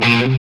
GTR ROCK 0EL.wav